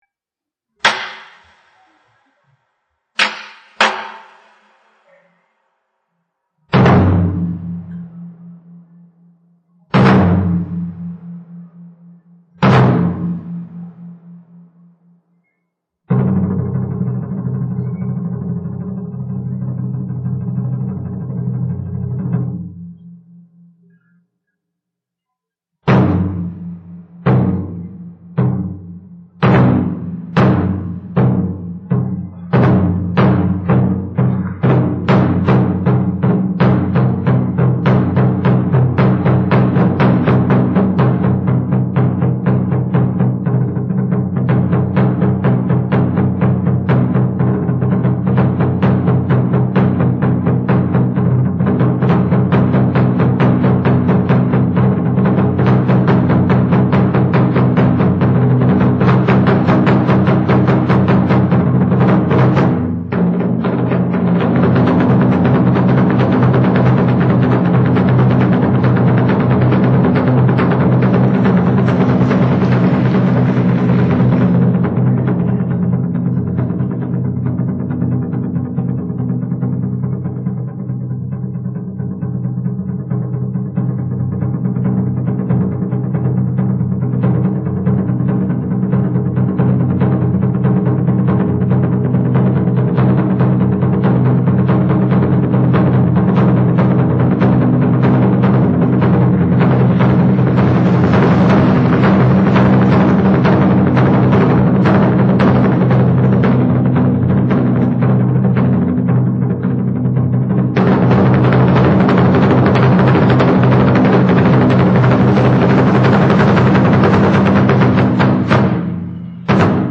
渐快的琴声，由快板到急板，将乐曲推向高潮．实在是扣人心弦．那飞速奔腾的旋律似山洪呼啸，如海涛汹涌，更似虞姬奔涌的情感冲破闸门一泻千里．．．．．． 90年代初，经过再创作形成现代京胡曲《夜深沉》。一改原来曲风形成，新乐曲流畅激昂，刚劲有力。